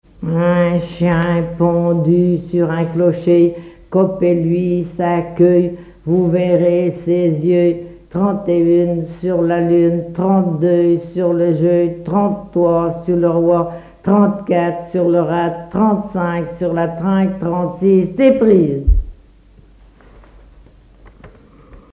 formulette enfantine
collecte en Vendée
répertoire enfantin